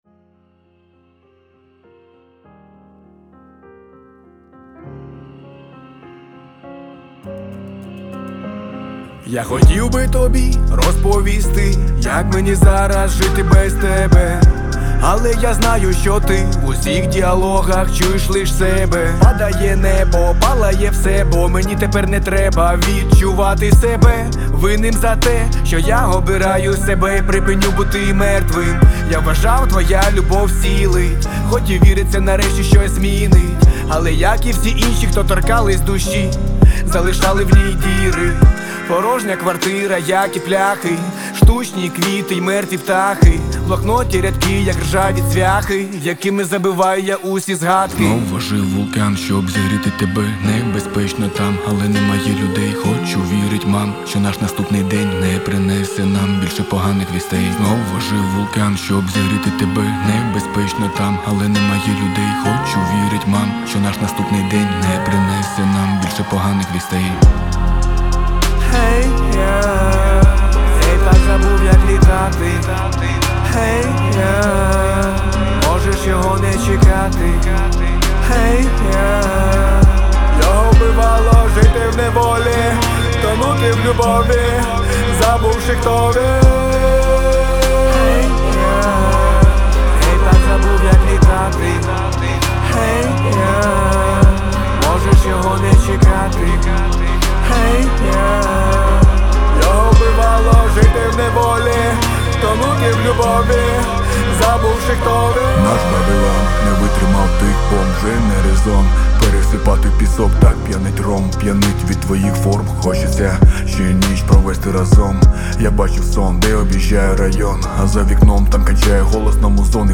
• Жанр:Реп